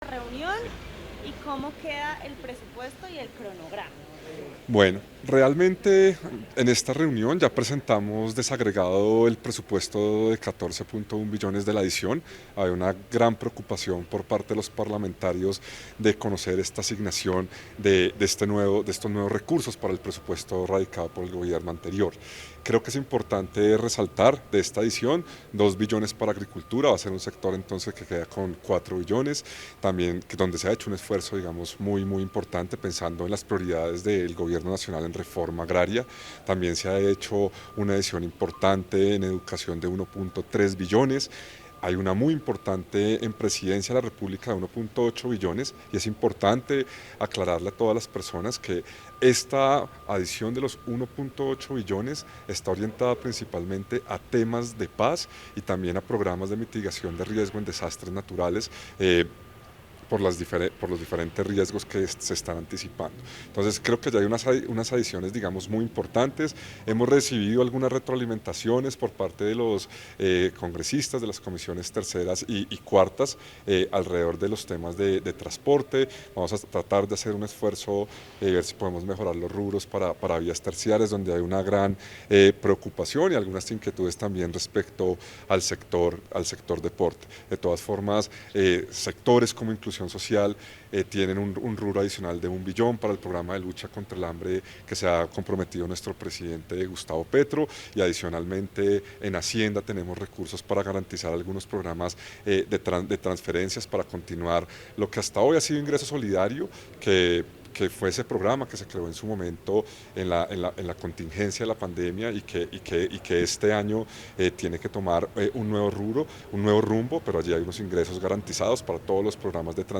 Declaraciones del Viceministro General de Hacienda, Diego Guevara, al término de la reunión con ponentes del Proyecto de Presupuesto parte 1